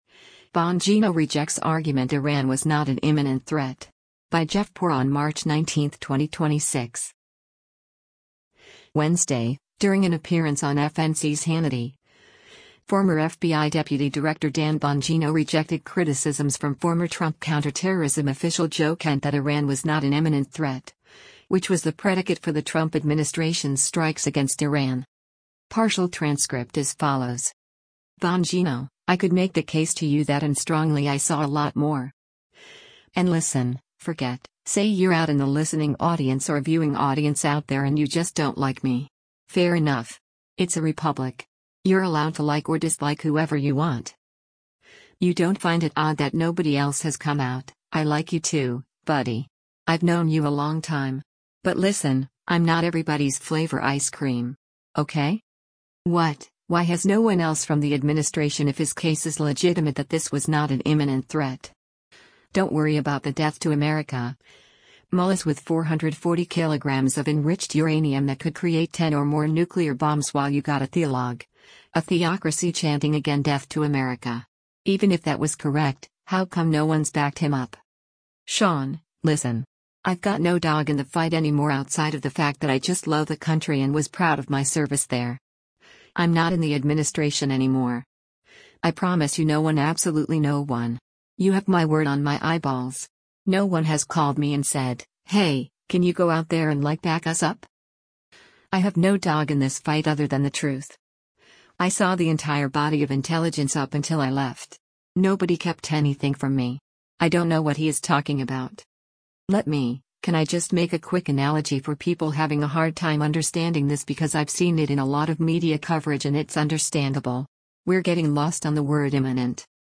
Wednesday, during an appearance on FNC’s “Hannity,” former FBI Deputy Director Dan Bongino rejected criticisms from former Trump counterterrorism official Joe Kent that Iran was not an imminent threat, which was the predicate for the Trump administration’s strikes against Iran.